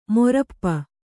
♪ morappa